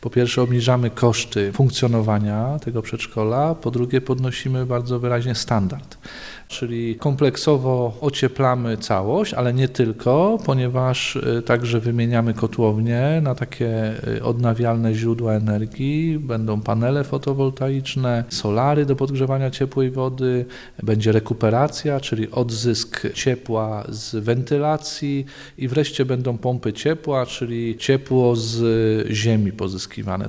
– To dobry kierunek inwestowania środków – powiedział burmistrz Mirosław Gąsik: